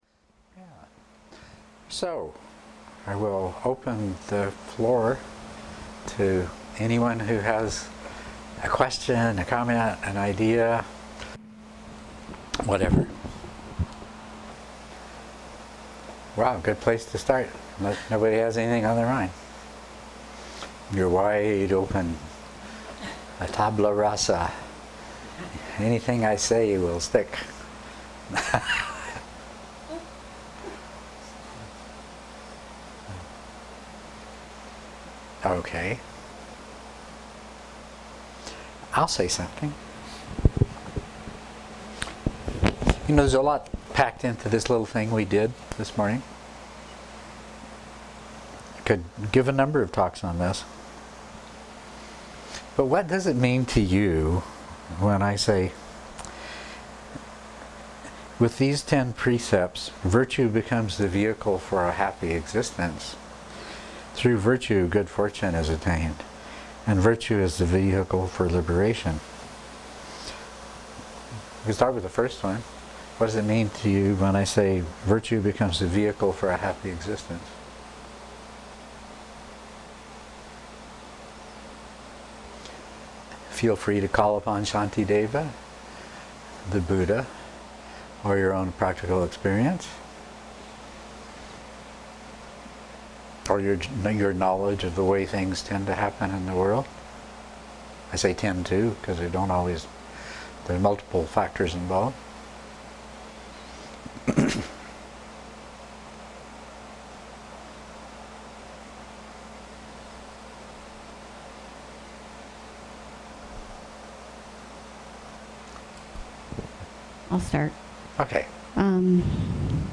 MP3 of the Dharma talk at the Stronghold